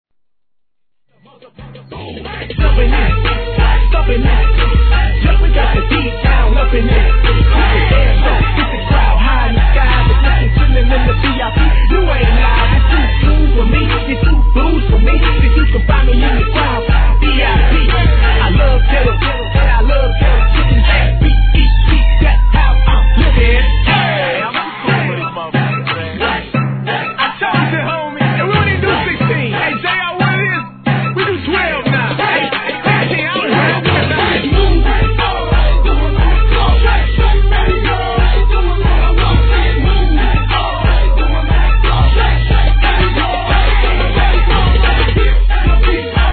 HIP HOP/R&B
(90 BPM)